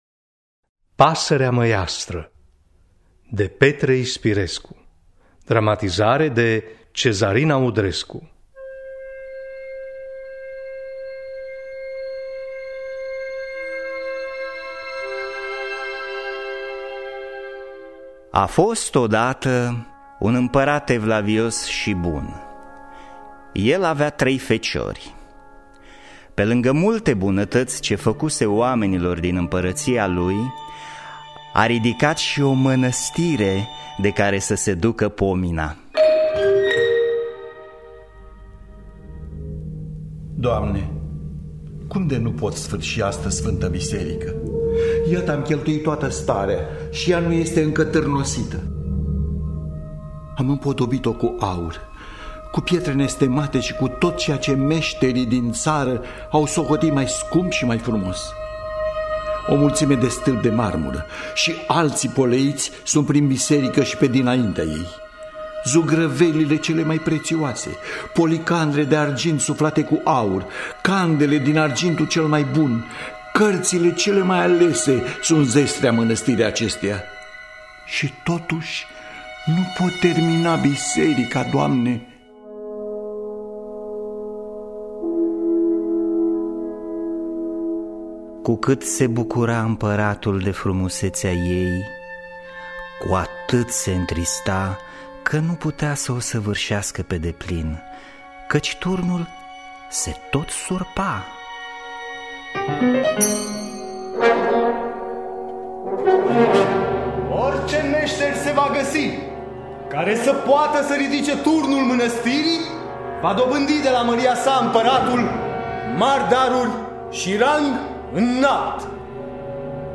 “Pasărea măiastră” de Petre Ispirescu. Adaptarea radiofonică